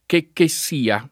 che che sia [ kekke SS& a ]